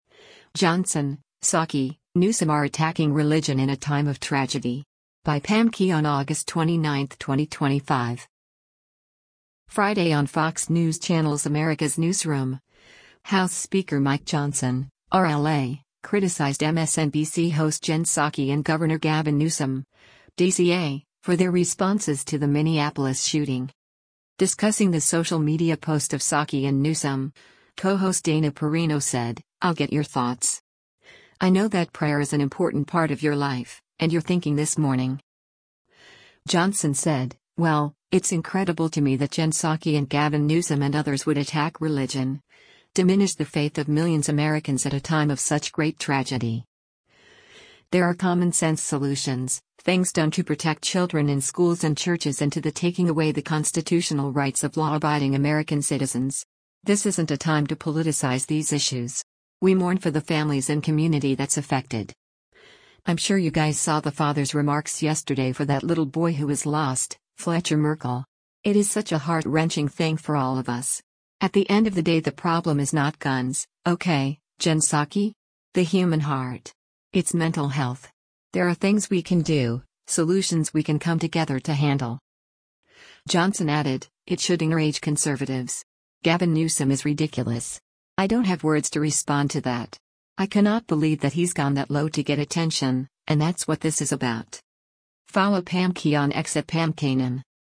Friday on Fox News Channel’s “America’s Newsroom,” House Speaker Mike Johnson (R-LA) criticized MSNBC host Jen Psaki and Gov. Gavin Newsom (D-CA) for their responses to the Minneapolis shooting.